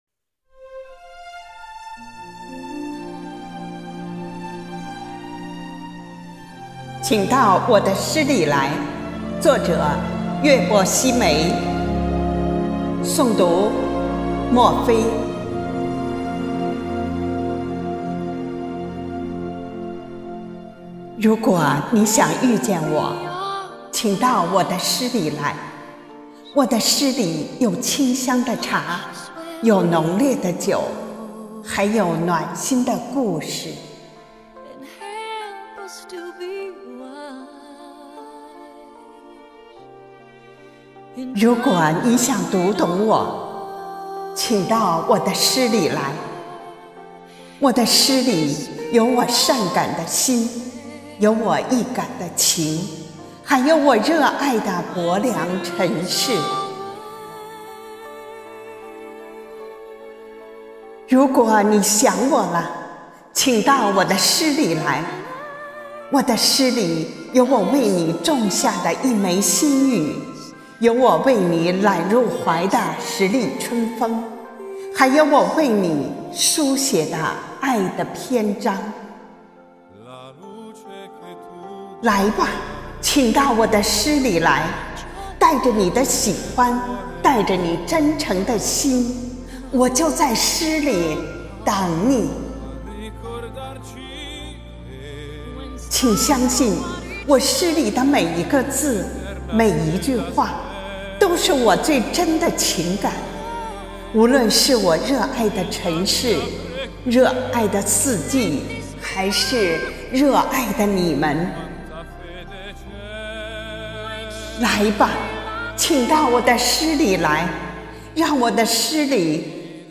暨八里庄西里支队第10场幸福志愿者朗诵会